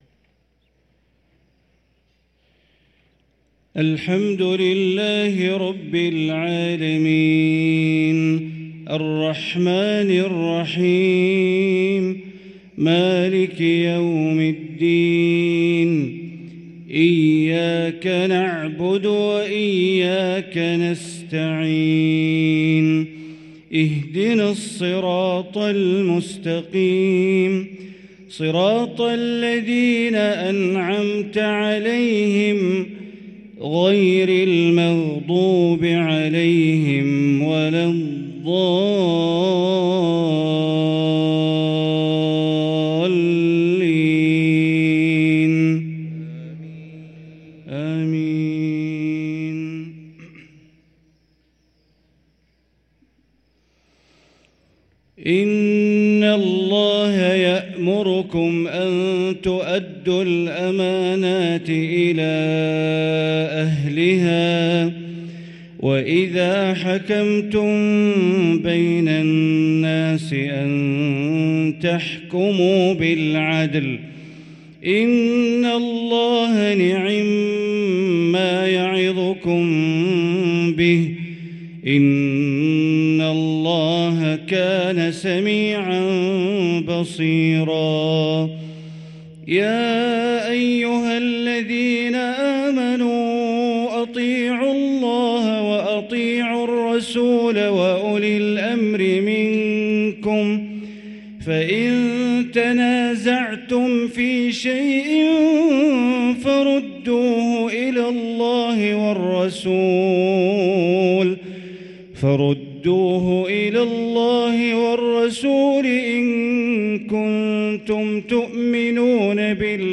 صلاة المغرب للقارئ بندر بليلة 30 شعبان 1444 هـ